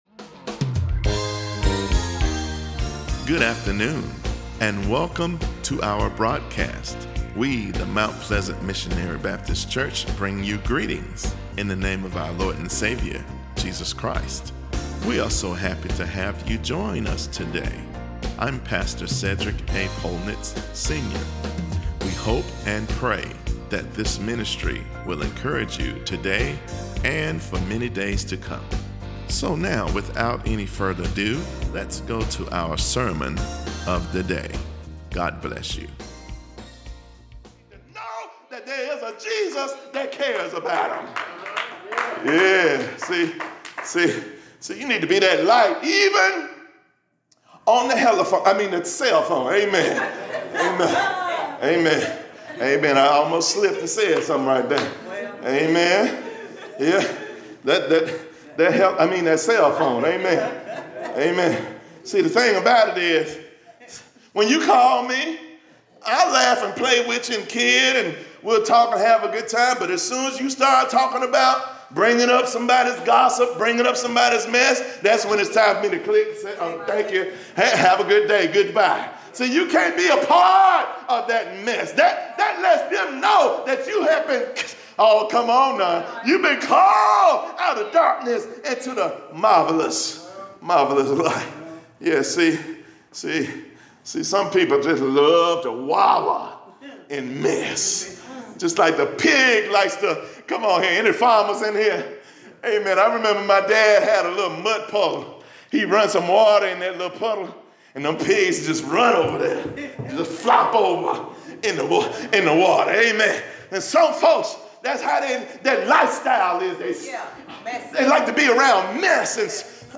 Closing Song: God has been Good